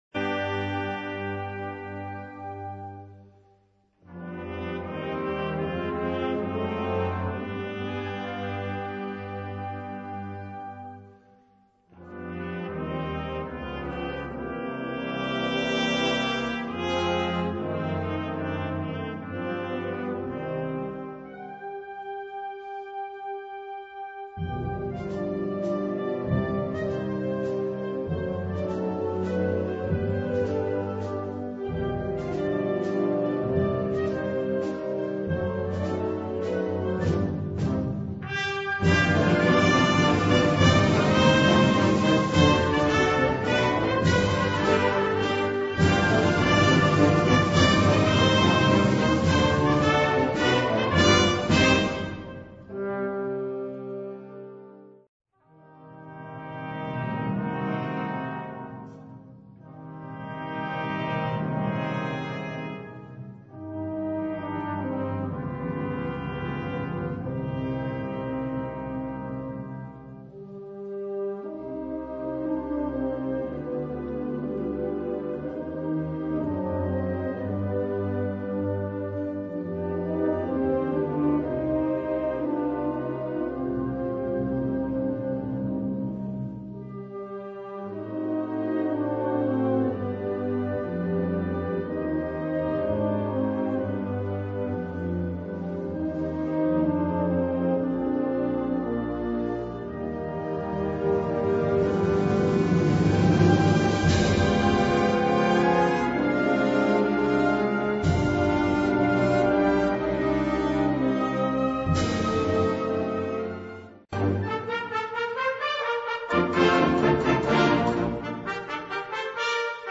Categorie Harmonie/Fanfare/Brass-orkest
Subcategorie Suite
Bezetting Ha (harmonieorkest)